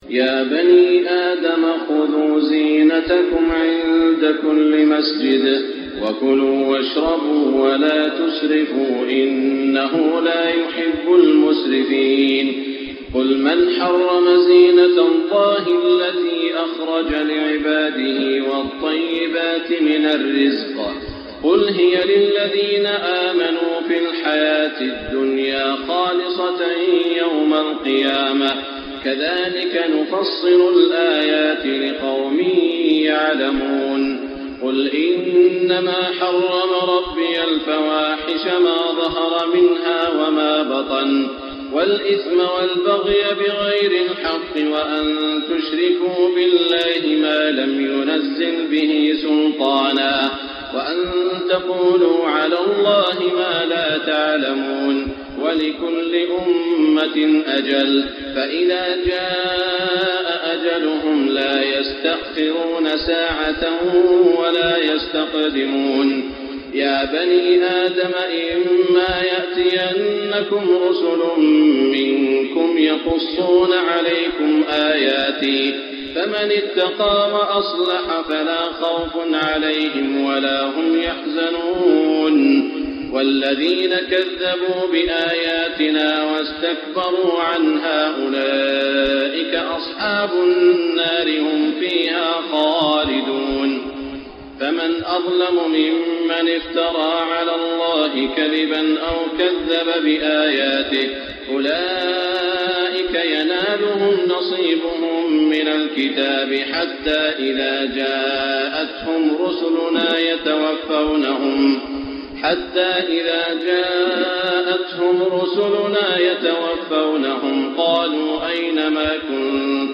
تهجد ليلة 28 رمضان 1425هـ من سورة الأعراف (31-93) Tahajjud 28 st night Ramadan 1425H from Surah Al-A’raf > تراويح الحرم المكي عام 1425 🕋 > التراويح - تلاوات الحرمين